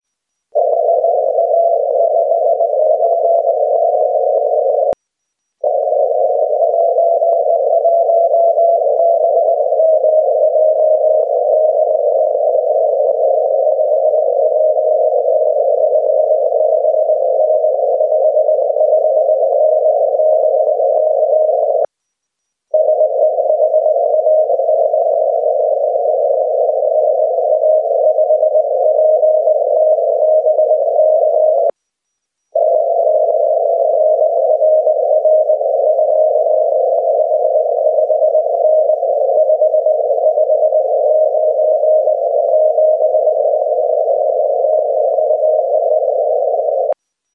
It was difficult to copy because of rapid QSB here.